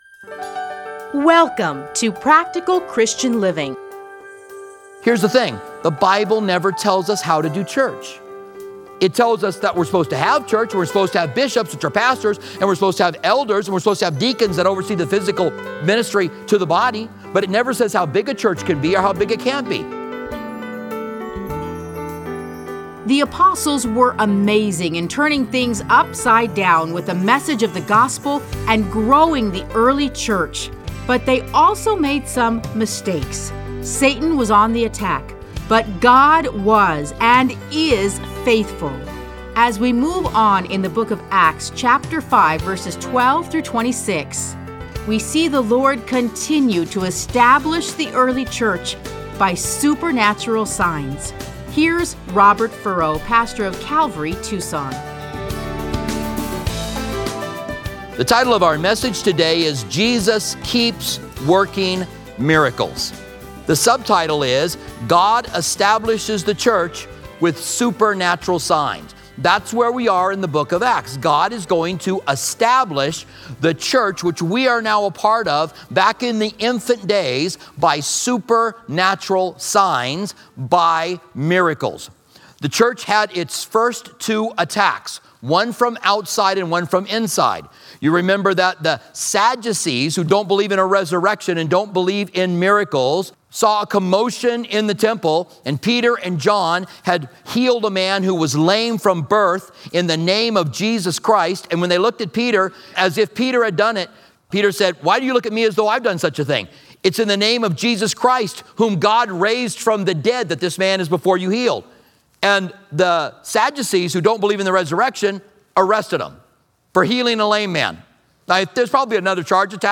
Listen to a teaching from Acts 5:12-22.